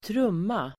Uttal: [²tr'um:a]